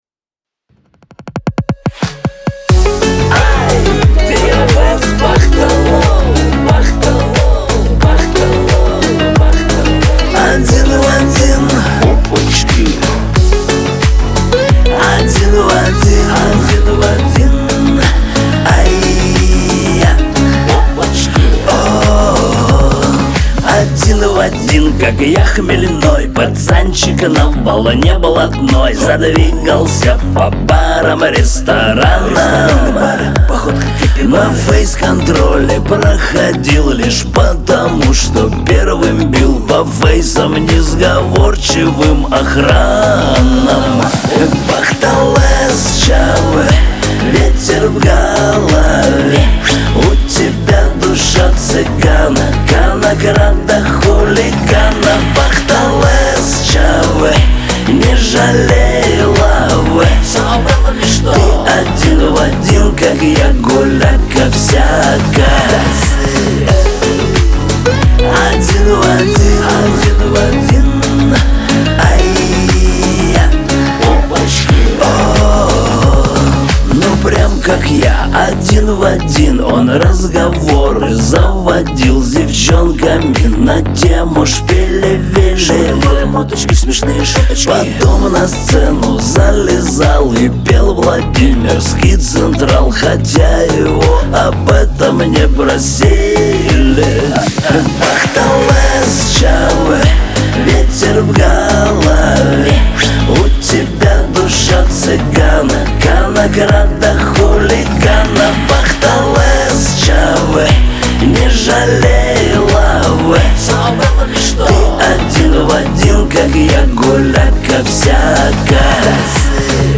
Лирика , Шансон